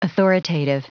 Prononciation du mot authoritative en anglais (fichier audio)
Prononciation du mot : authoritative